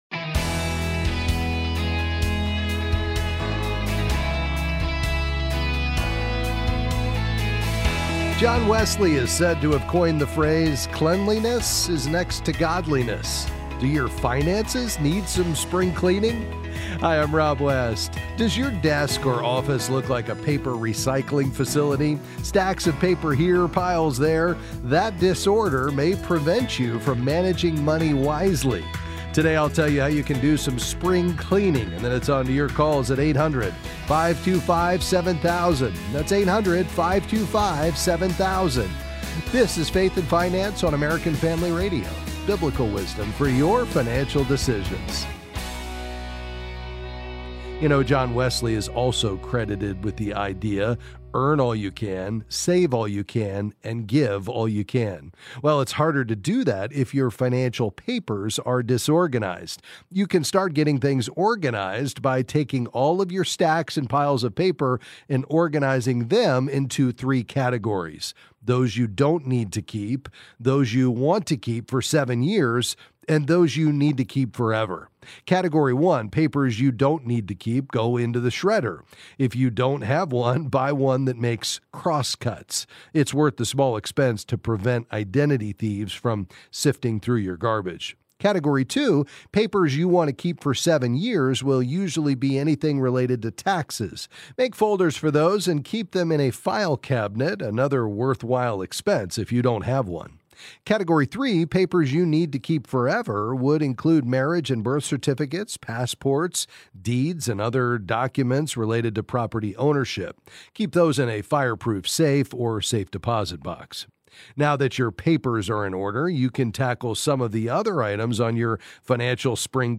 Then he’ll answer your calls on various financial topics.